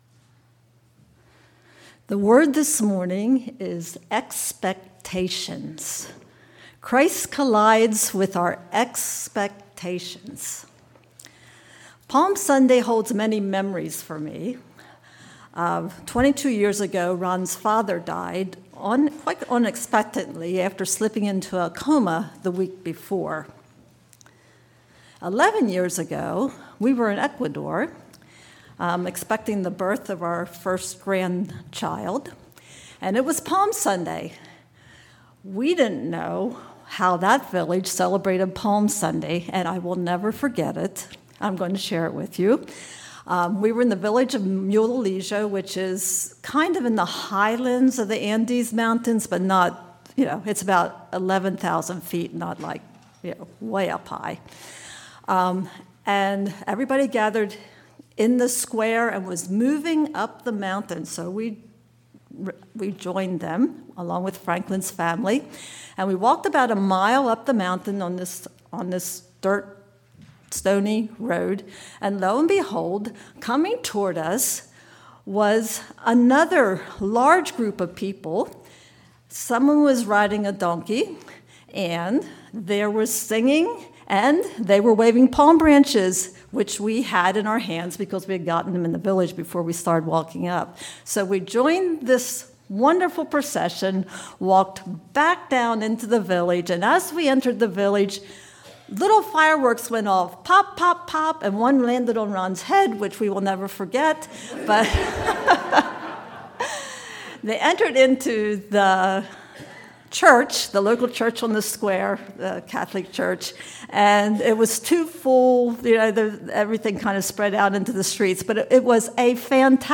4/13/25 Sermon